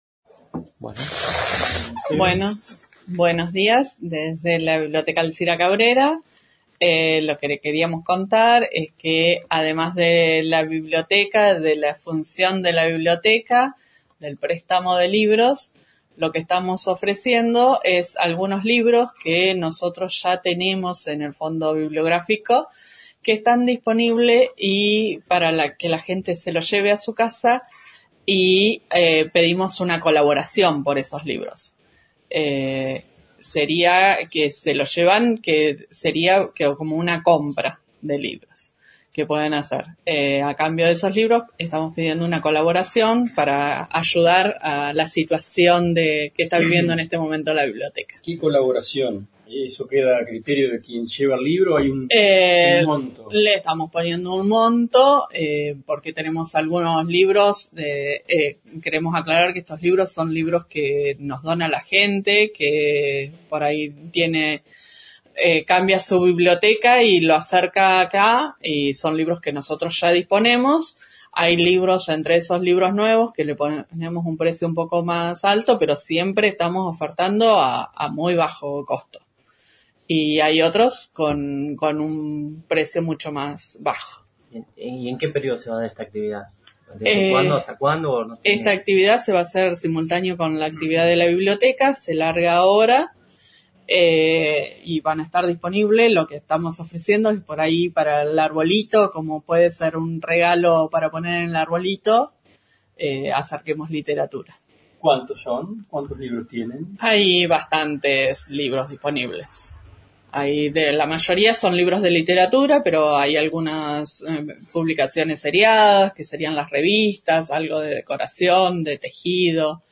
Conferencia de Prensa